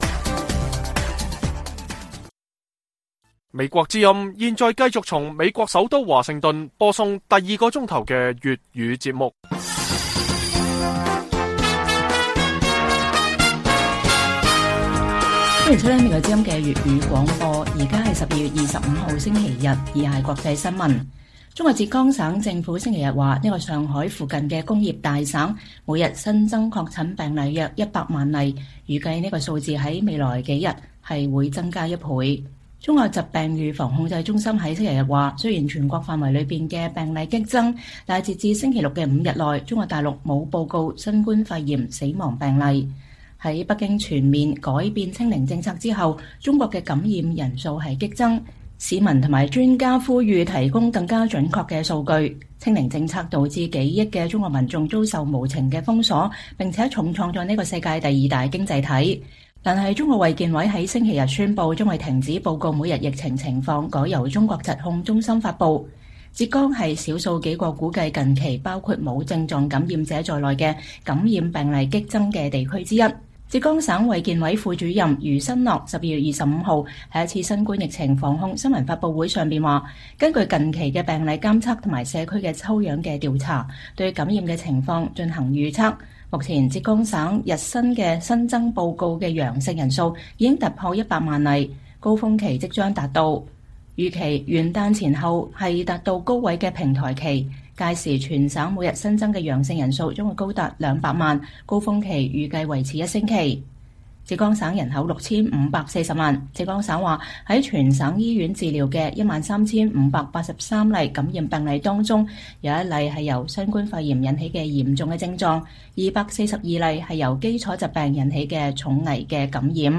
粵語新聞 晚上10-11點：中國疫情感染人數暴增 浙江每天新增百萬病例 預計還會倍增